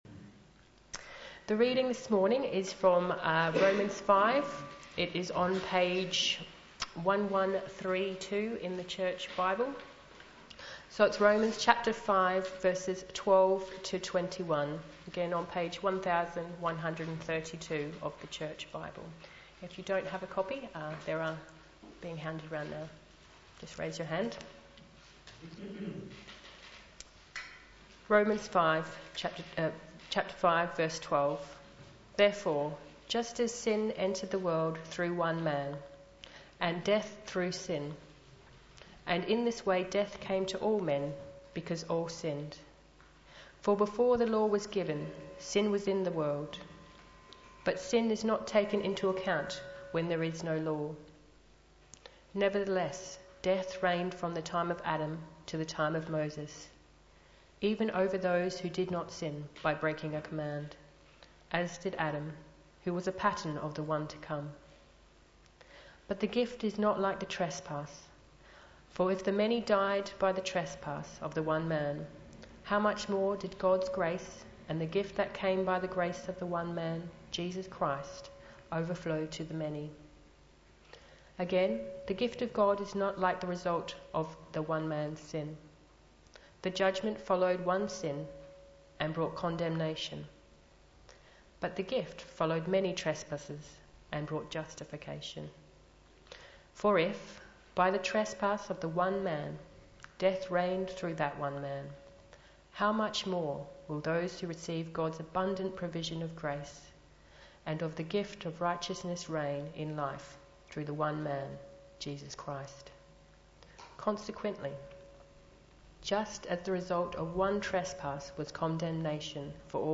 Sunday Service
From death to life Sermon